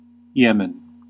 Ääntäminen
Ääntäminen US Tuntematon aksentti: IPA : /ˈjɛmən/ Haettu sana löytyi näillä lähdekielillä: englanti Käännös Erisnimet 1. Υεμένη {f} (Yeméni) Määritelmät Erisnimet Country in the Middle East.